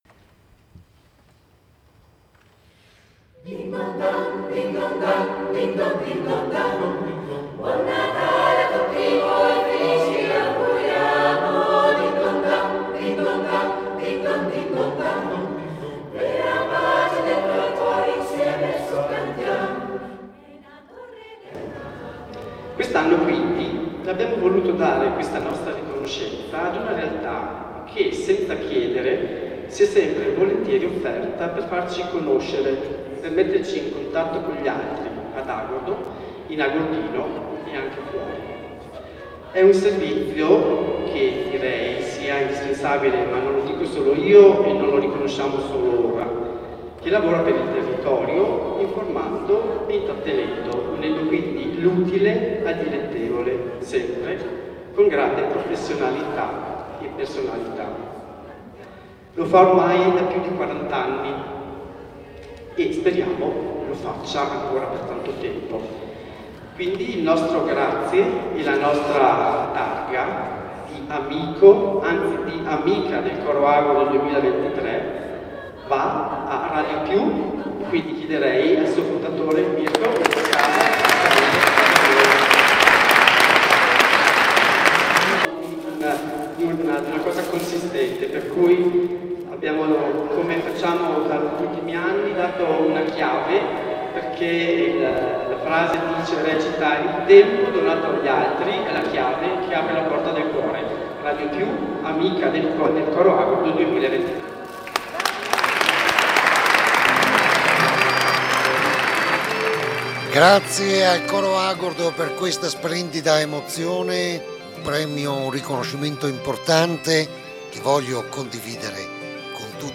GIORNALE RADIO PRINCIPALE DELLE 12.30